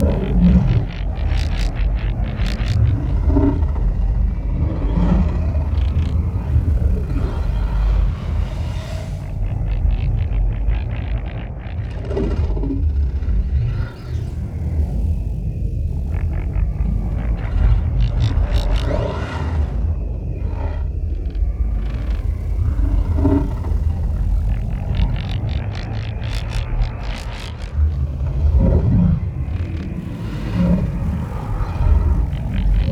sloth_idle.ogg